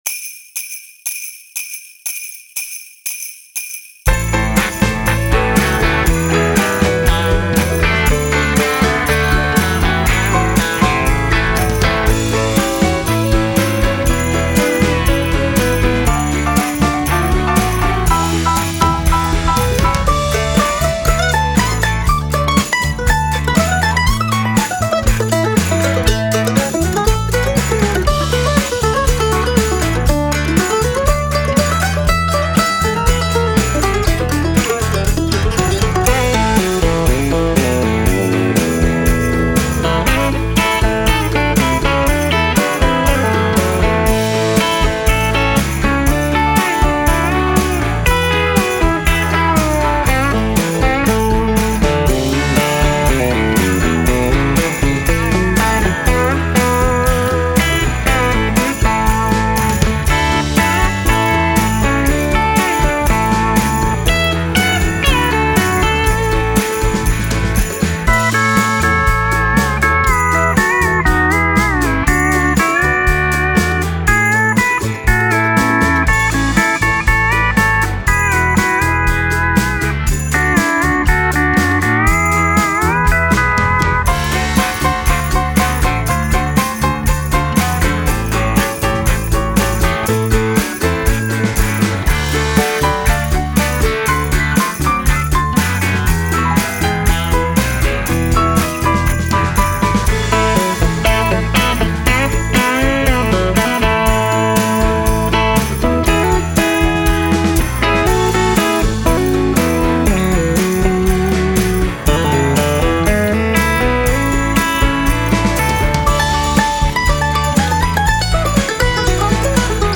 Christmas & Seasonal Themes